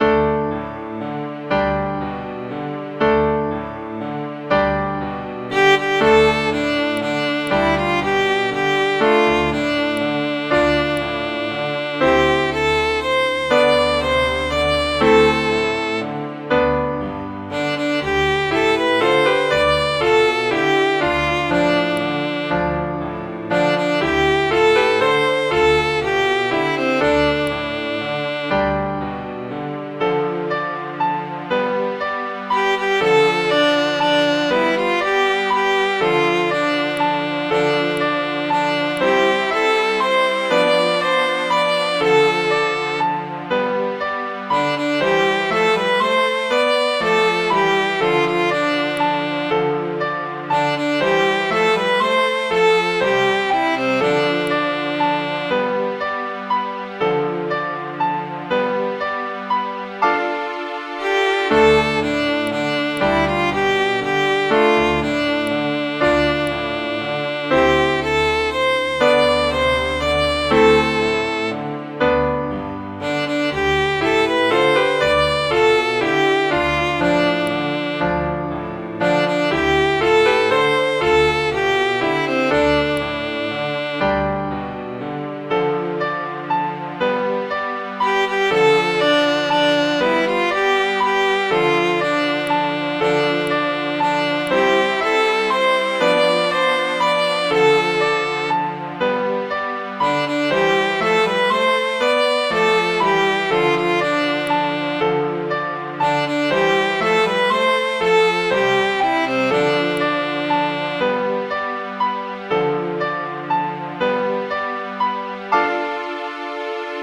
Midi File, Lyrics and Information to Here's Adieu to All Judges and Juries